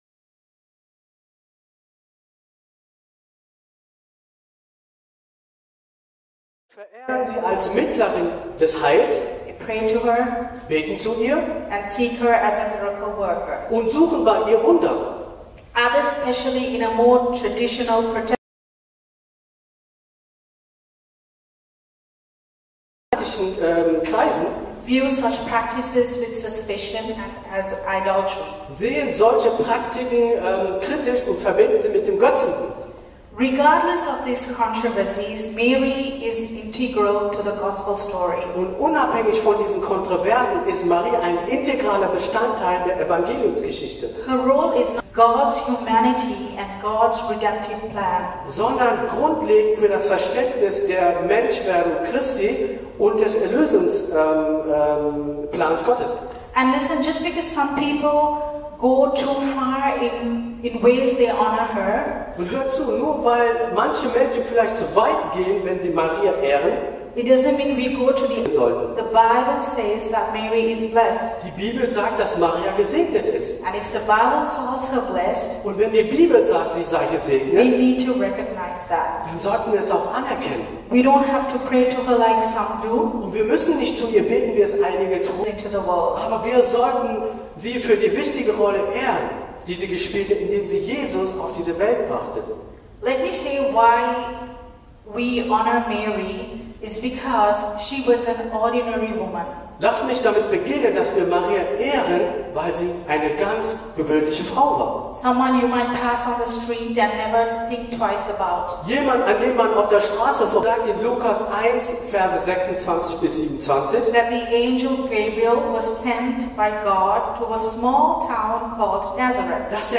Neue Predigtreihe :Weihnachten im ICB:!